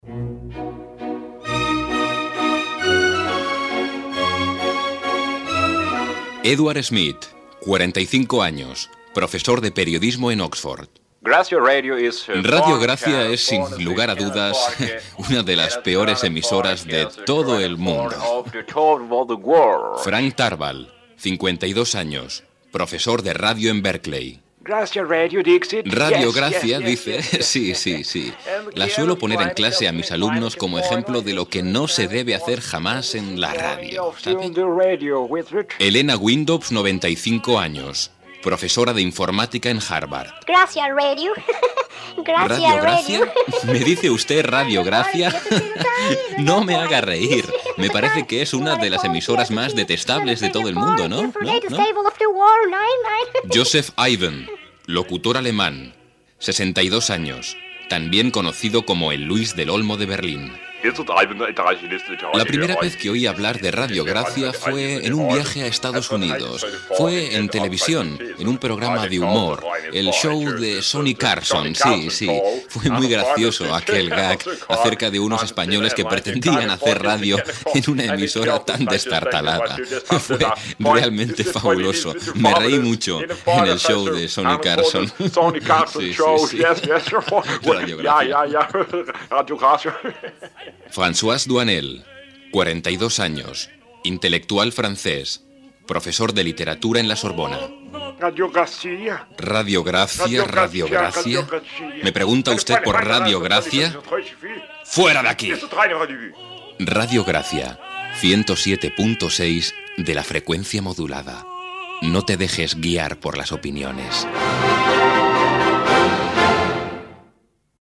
Indicatiu de l'emissora ("autoritats universitàries" i locutors internacionals opinen de Ràdio Gràcia)